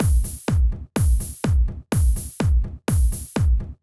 Drumloop 125bpm 01-C.wav